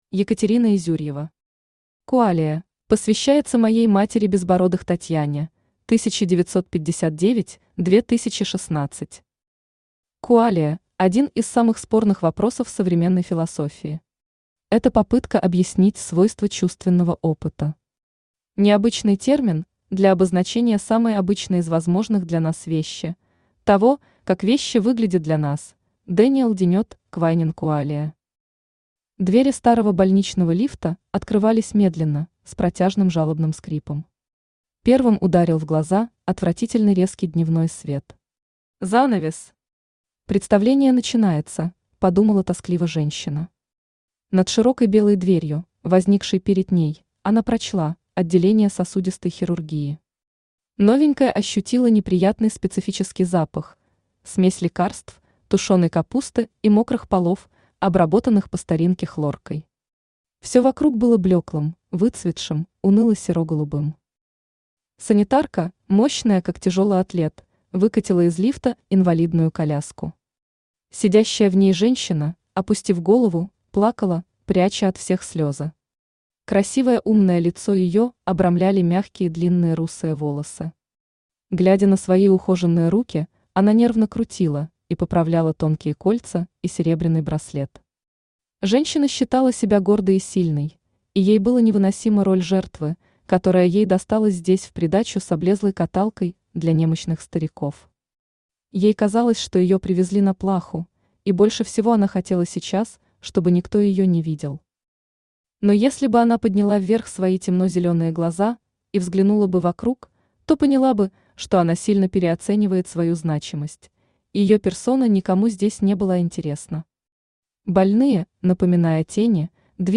Аудиокнига Qualia | Библиотека аудиокниг
Читает аудиокнигу Авточтец ЛитРес.